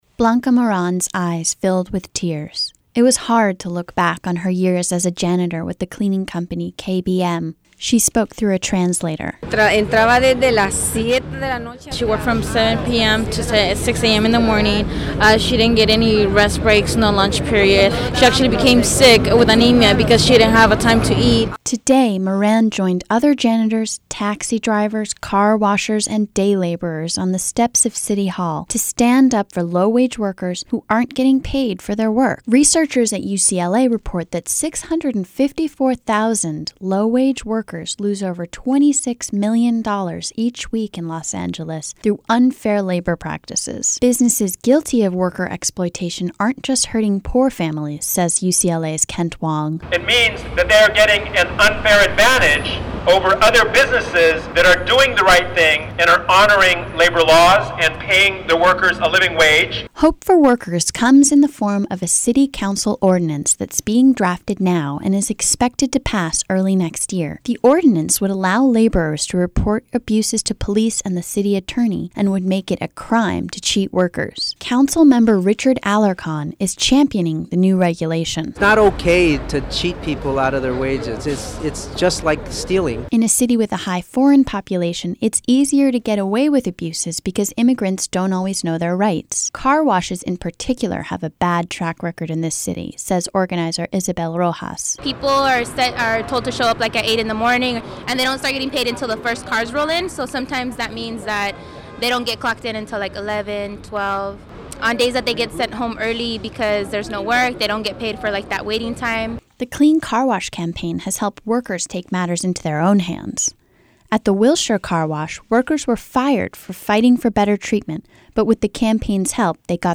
Councilmember Richard Alarcon is championing the new regulation.
"It's not okay to cheat people out of their wages, it's just like stealing," Alarcon said.